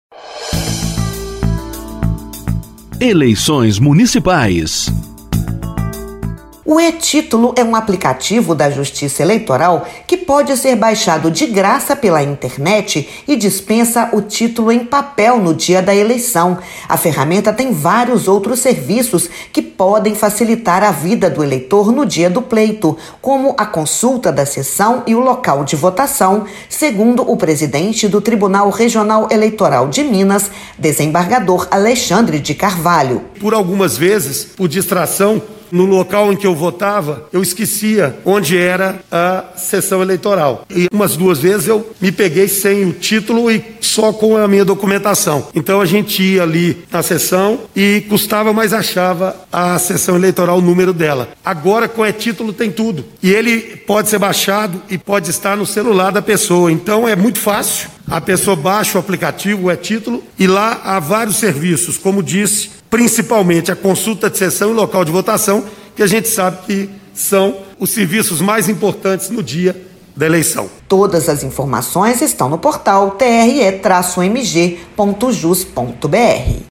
Ouça a explicação do presidente do Tribunal Regional Eleitoral em Minas, desembargador Alexandre de Carvalho, sobre como votar.